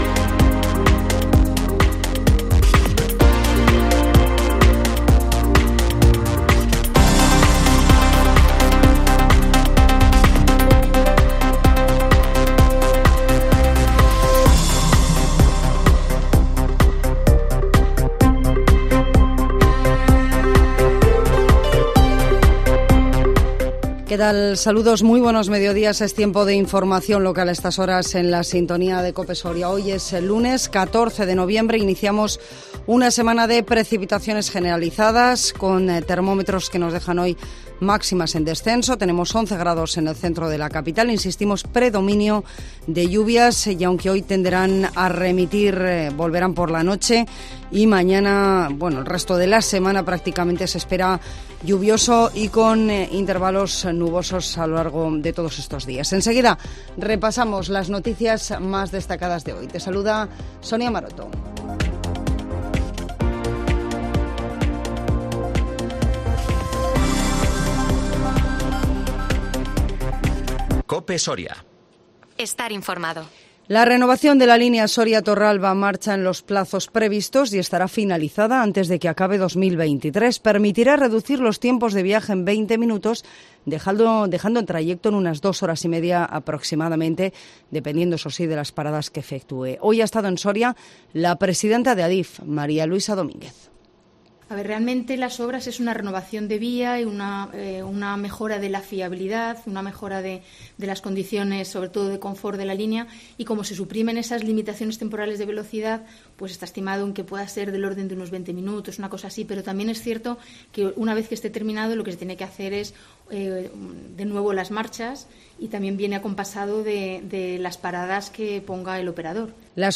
INFORMATIVO MEDIODÍA COPE SORIA 14 NOVIEMBRE 2022